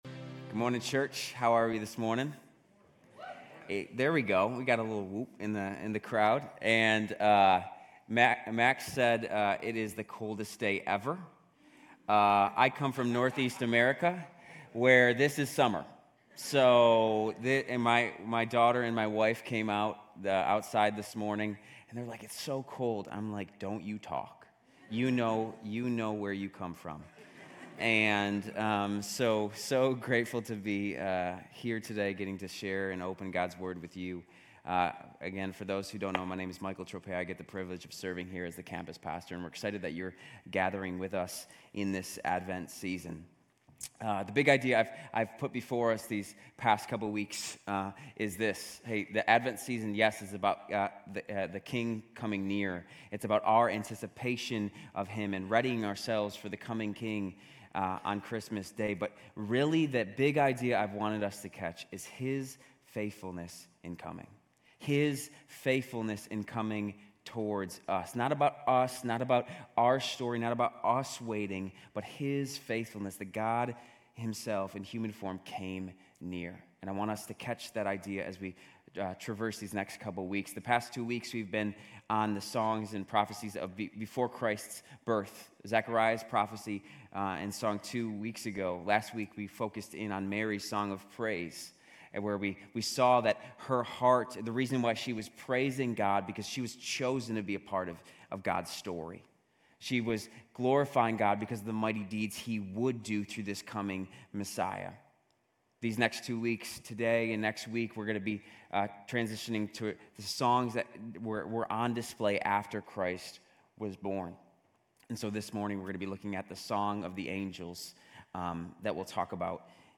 GCC-UB-December-10-Sermon.mp3